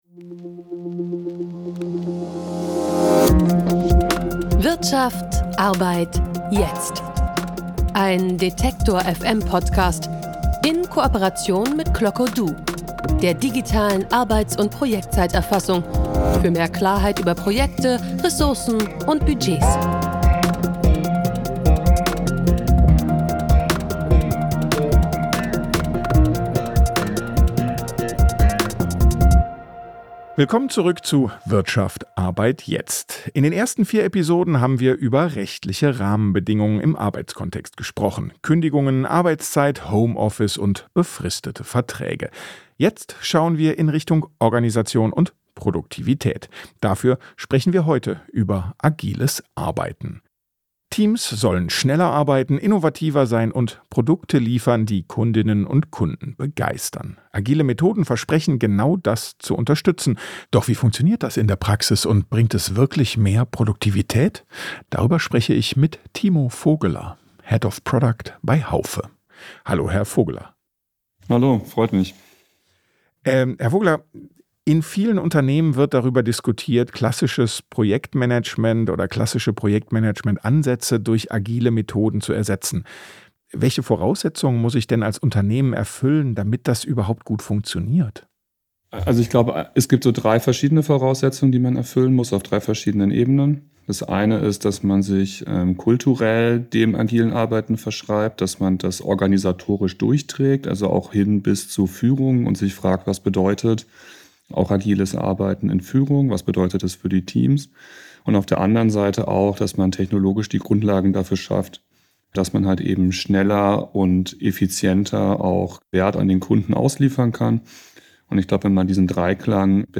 Agil versus klassisch Im Gespräch zeigt sich, dass Unternehmen und Teams individuell prüfen müssen, ob und welche agile Methoden zu ihnen passen.